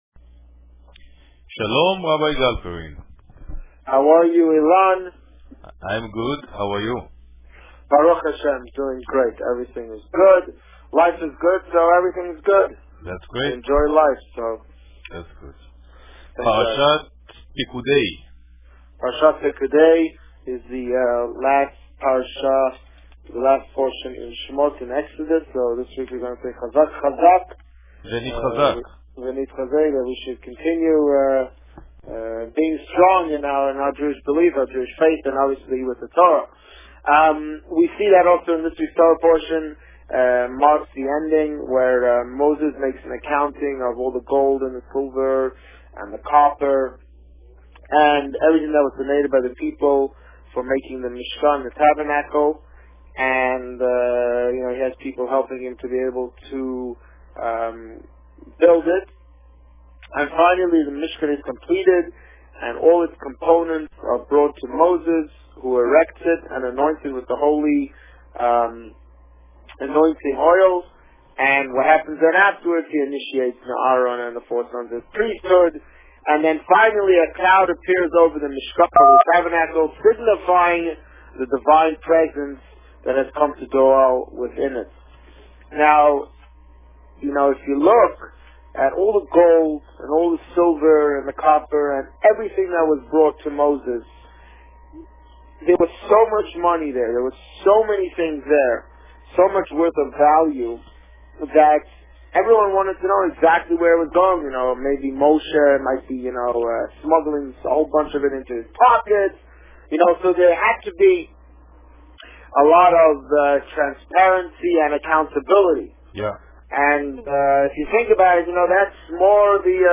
The Rabbi on Radio
Parsha Pekudei and Purim preparations Published: 03 March 2011 | Written by Administrator On March 3, 2011, the Rabbi spoke about Parsha Pekudei and the upcoming Purim festivities. Listen to the interview here .